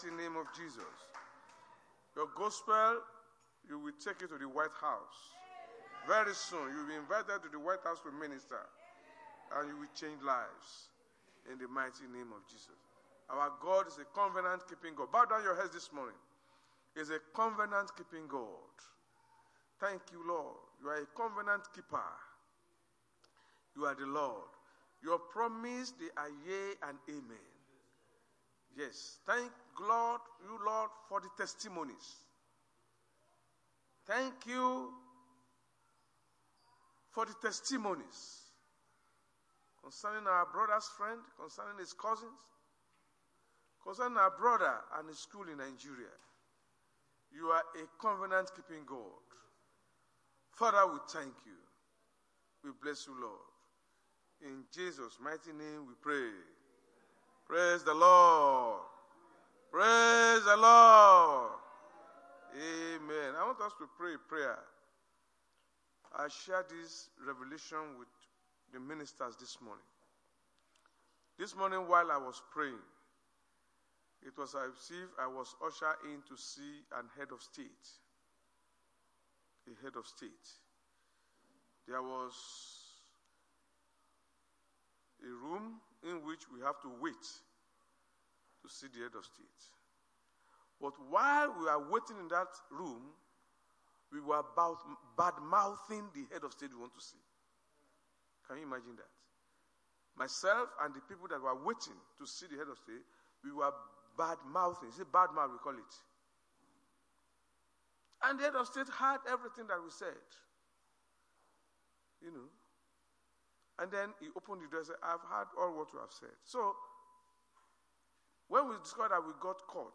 RCCG House Of Glory Sunday Sermon: A Different Kind Of Peace | RCCG-HOUSE OF GLORY
Service Type: Sunday Church Service